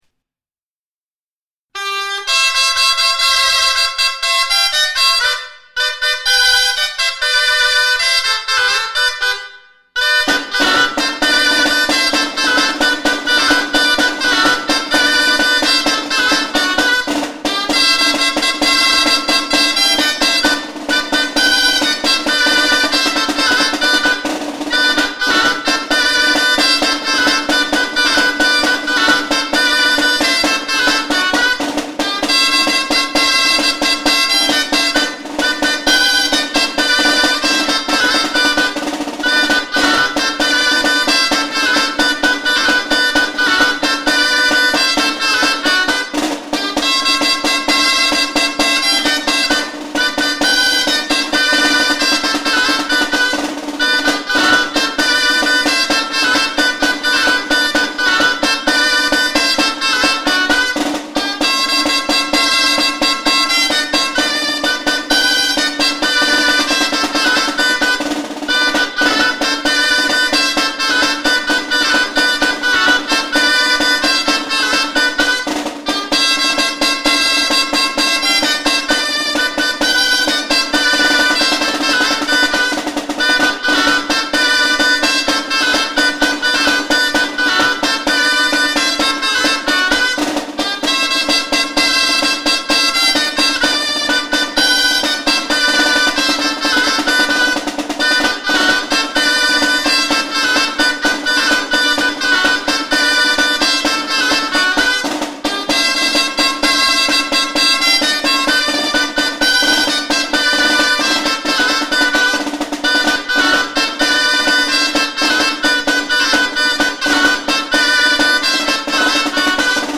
Paloteadoa, «El Villar» Paseoa.
Paloteadoa, «Don José». Ortzadarko gaiteroak Corella.